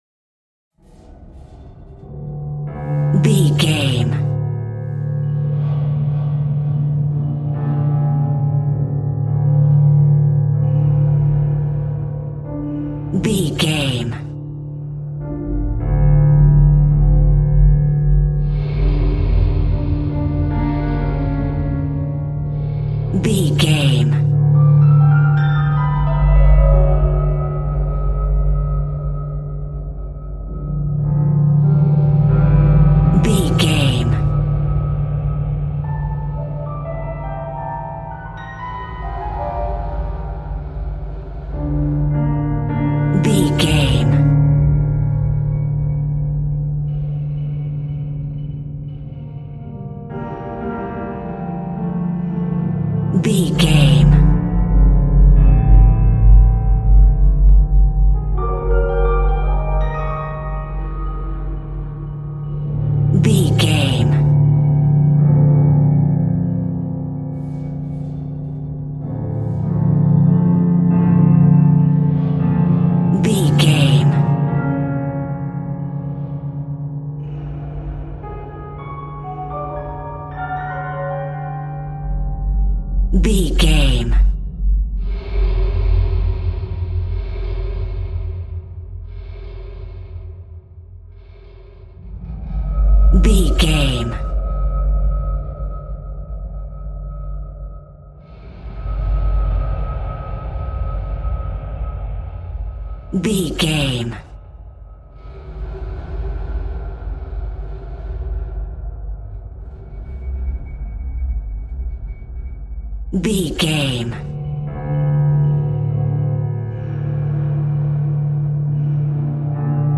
Aeolian/Minor
D
Slow
tension
ominous
dark
suspense
eerie
piano
cymbals
gongs
viola
french horn trumpet
taiko drums
timpani